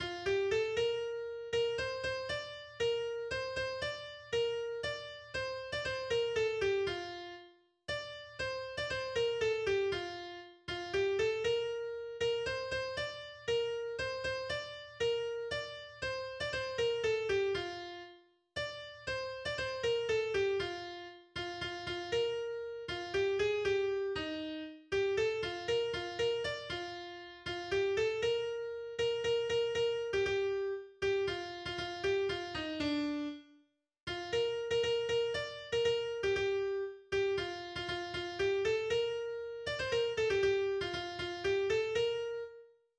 spöttisch-kritisches Gesellschaftslied